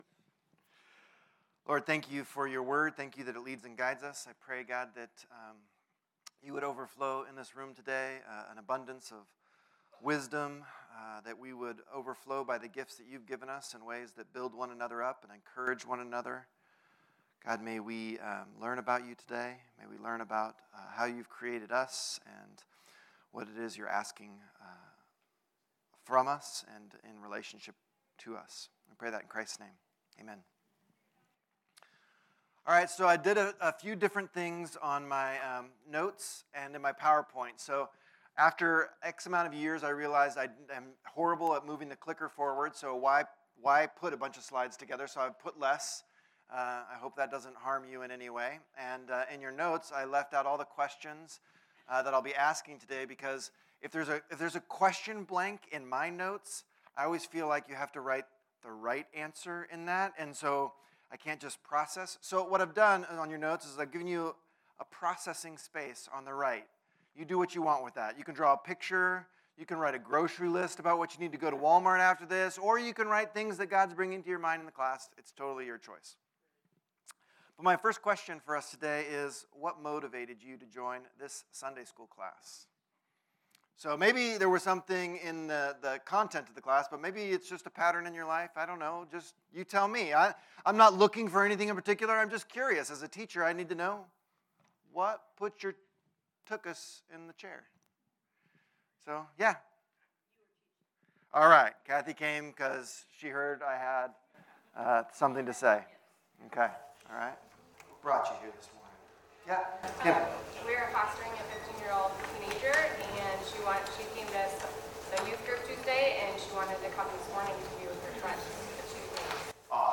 Type: Sunday School